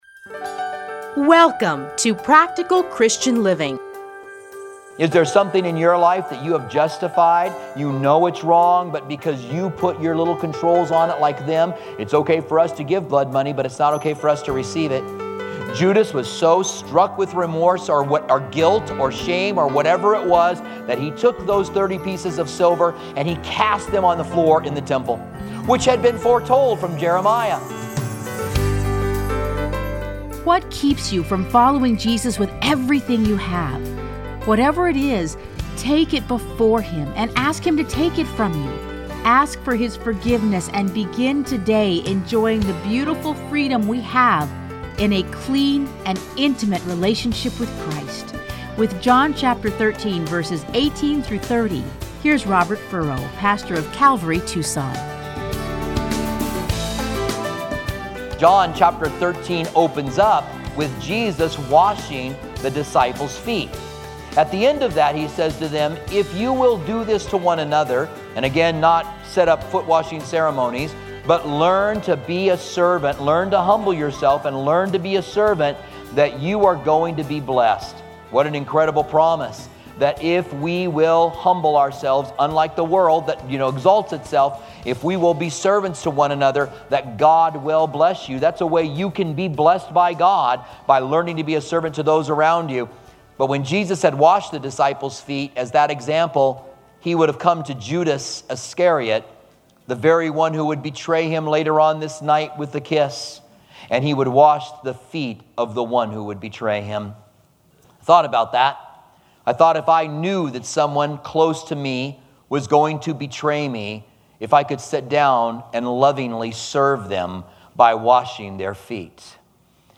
Listen to a teaching from John 13:18-30.